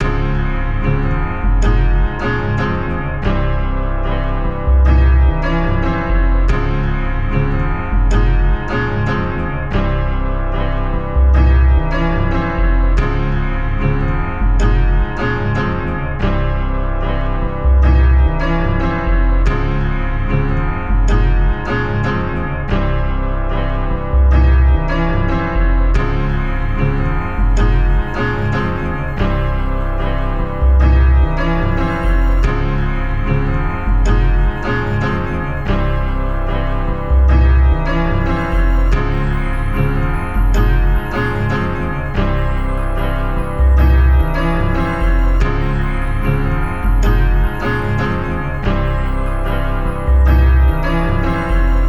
Chop & Melody (Ye Meets Polo).wav